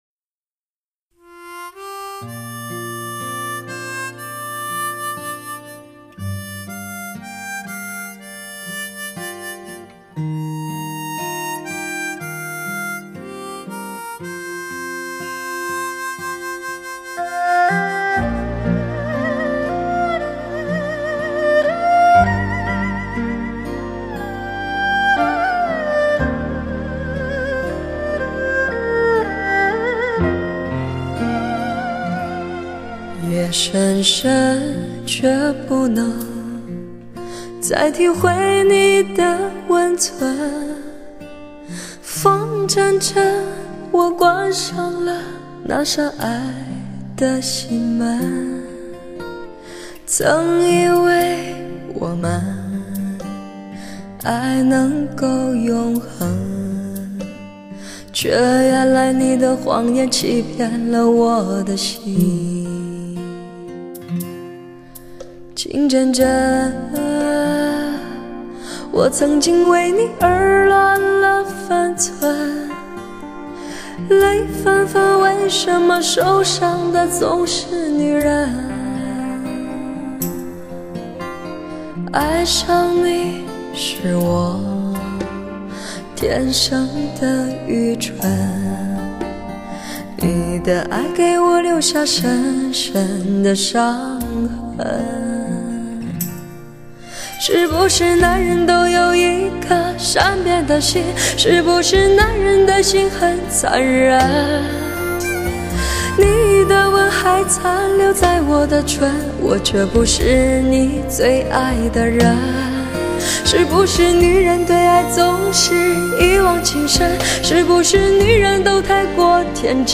试听音质96K